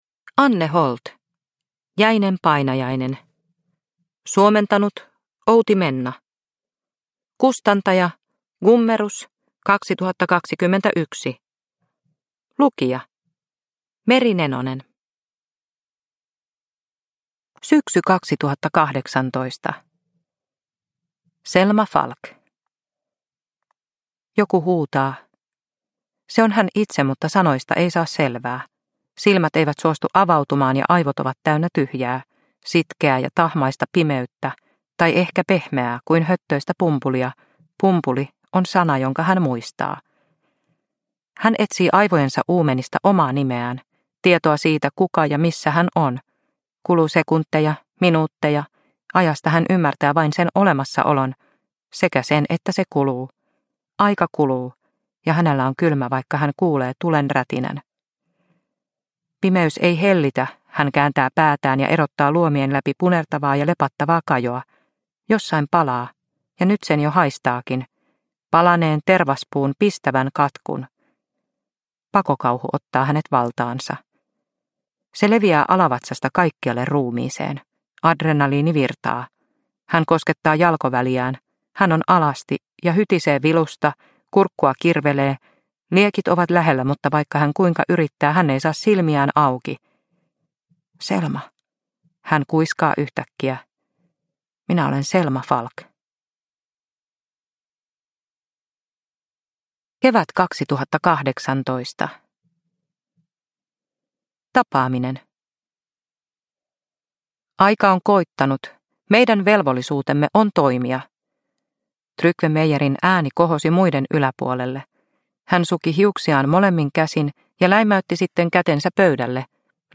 Jäinen painajainen – Ljudbok – Laddas ner